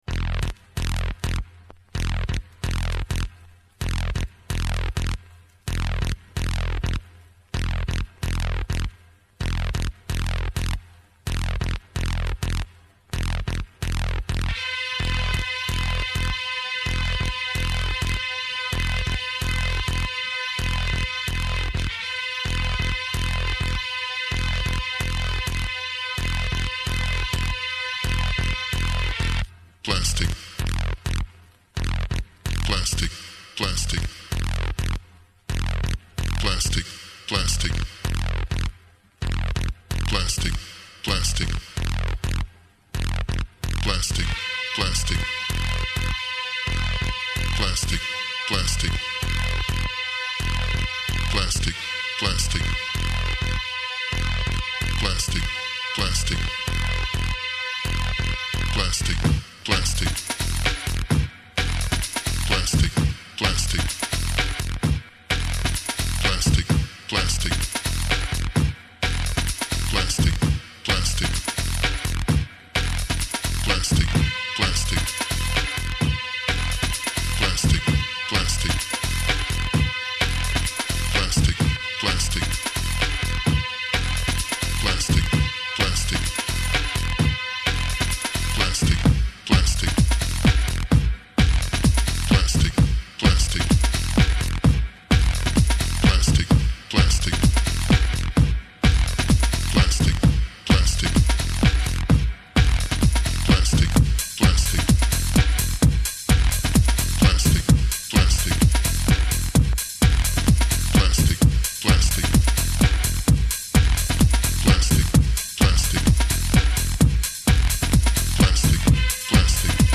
House 1993 Durata > 52 minuti